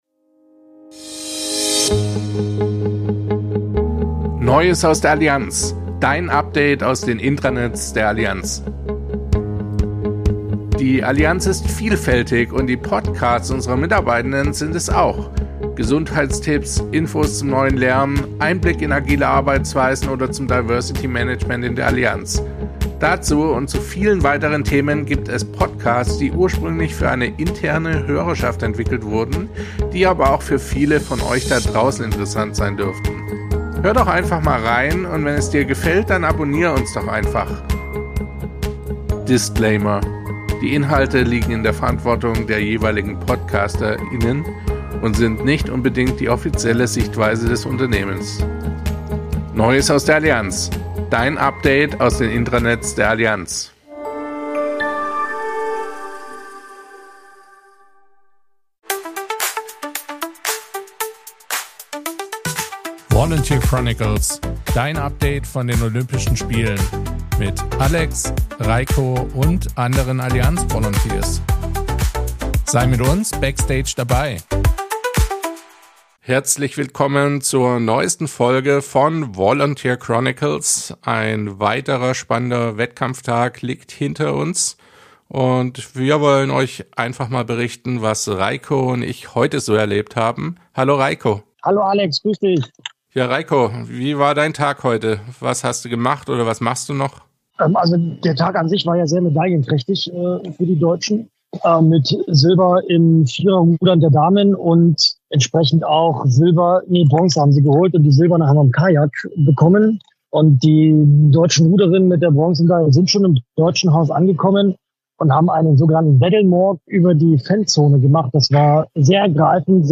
Beschreibung vor 1 Jahr Sei bei dem größten Sportevent der Welt backstage dabei. Allianz Volunteers geben dir exklusive Einblicke hinter die Kulissen von Paris 2024.